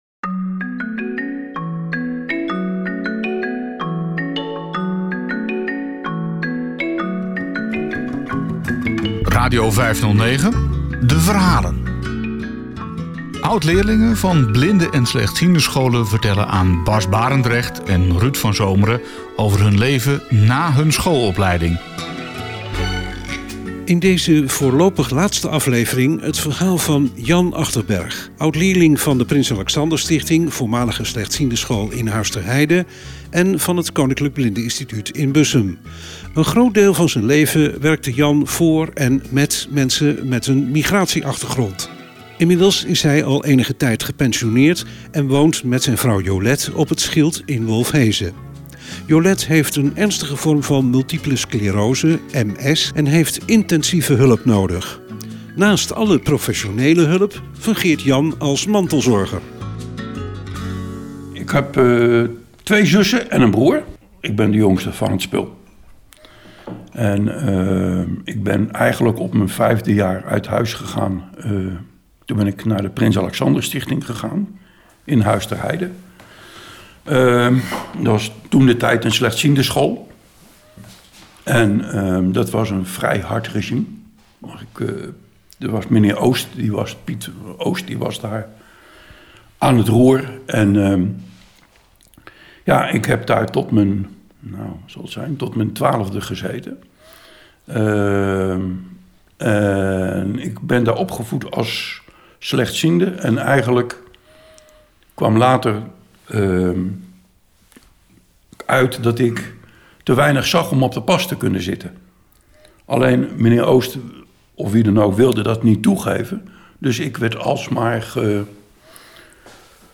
Oud leerlingen van van voormalige blindenscholen vertellen in deze podcast over hun leven. Hoe was het om te wonen, leven en leren op een blindeninstituut met bijbehorend internaat.